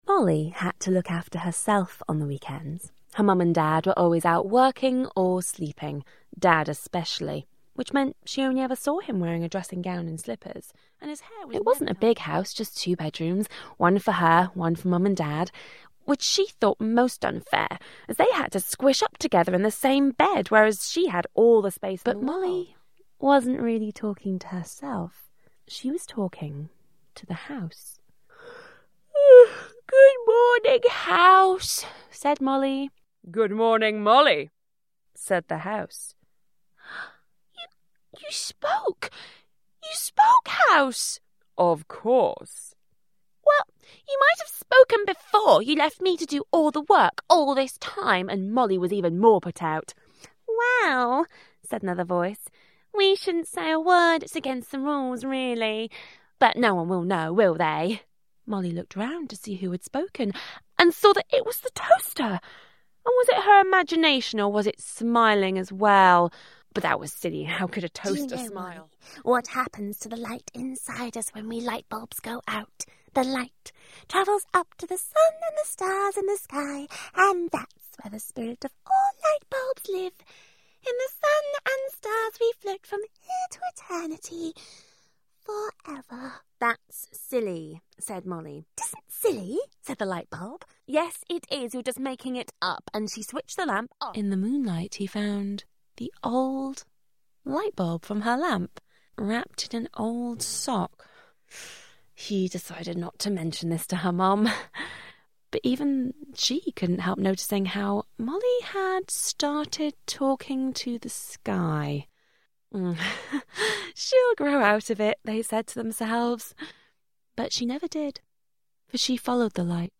Children's Book Narration - UK Accents
Narrating Children's Book "Bink" with Additional Multiple Character Voice